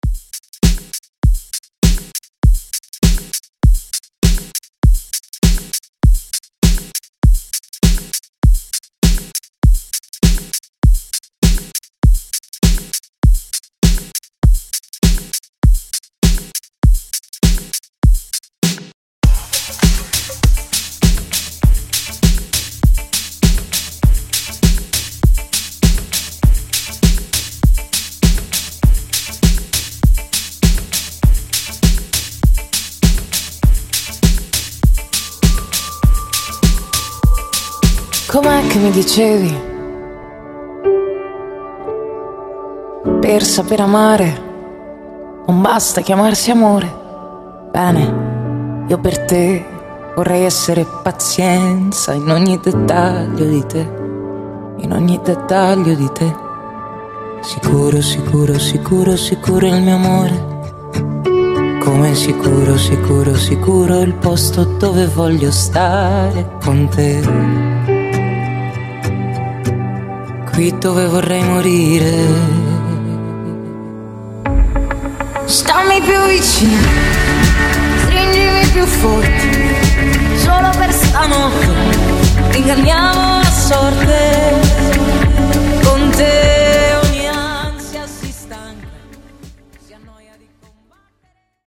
Genre: DANCE
Clean BPM: 132 Time